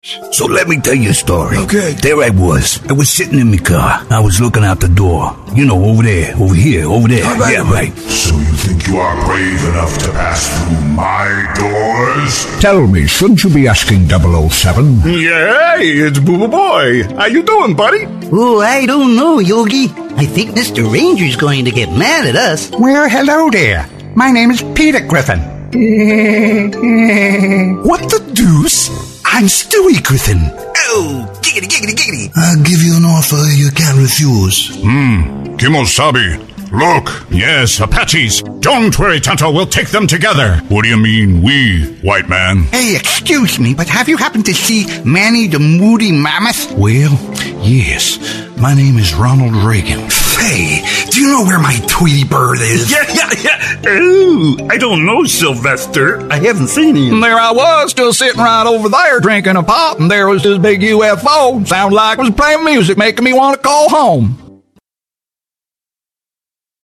Sprechprobe: Sonstiges (Muttersprache):